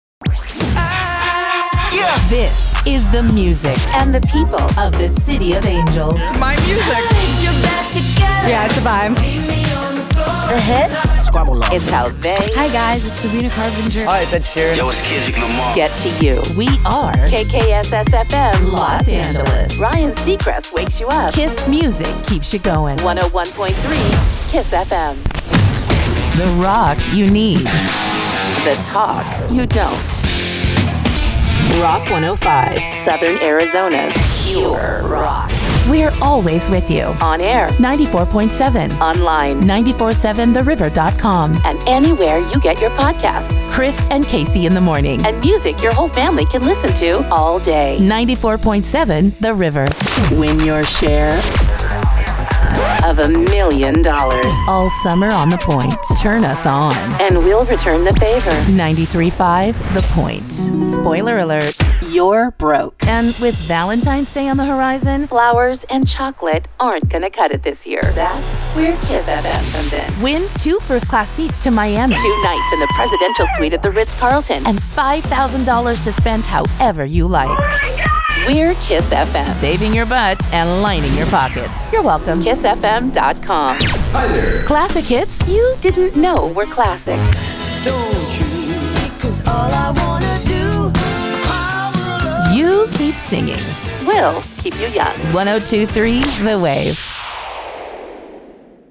Her vocal range goes from upbeat and fun to confident, to warm and mom-like,
She is a full-time voice actor with a home studio and offers fast turnaround making it easy to get high-quality, polished audio on your schedule. Imaging Demo: Imaging Demo Commercial Voice Demos: Overall Demo Spanish Demo request a free demo